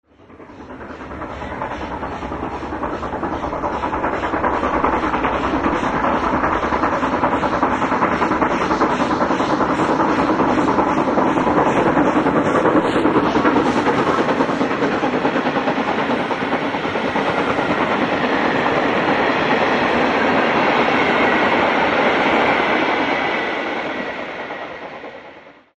This third volume of tracks are all recordings of steam hauled trains on the main line heard from the lineside not all of which have appeared on the web site.
3. I had to include this next recording which, while far from technically perfect, is of a loco which only rarely worked on the main line and it was even rarer to find the loco working a train single handed.
As I had no chance of travelling I went out to try to get a lineside recording of the engine passing Neville Hill on the climb out of Leeds as it passed on its way back to York in the evening.
The roar from the chimney would have been audible all the way to Cross Gates had a Hull bound DMU and an aircraft not intervened!